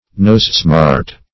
Search Result for " nosesmart" : The Collaborative International Dictionary of English v.0.48: Nosesmart \Nose"smart`\, n. (Bot.) A kind of cress, a pungent cruciferous plant, including several species of the genus Nasturtium .